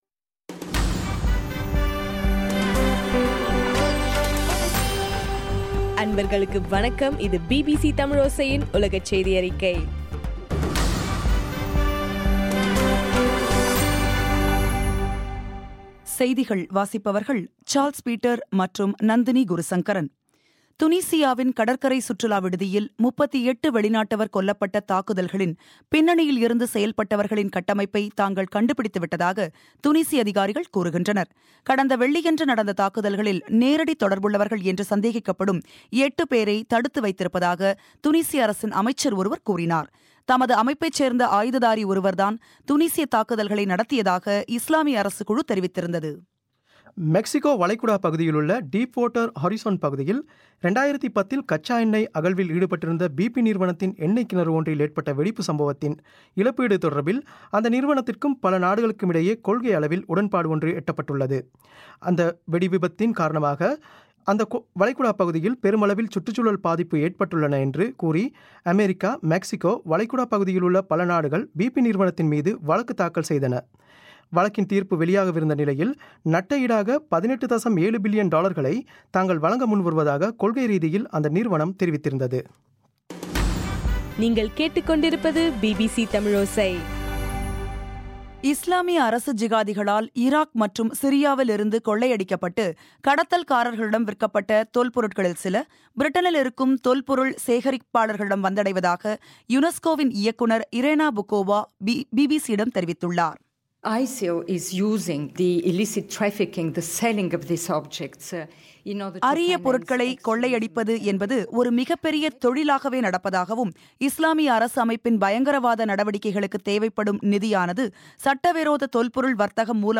ஜுலை 2 பிபிசியின் உலகச் செய்திகள்